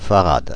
Ääntäminen
Ääntäminen France (Paris): IPA: /fa.ʁad/ Paris: IPA: [fa.ʁad] Haettu sana löytyi näillä lähdekielillä: ranska Käännös Konteksti Substantiivit 1. фарад metrologia, sähkö Suku: m .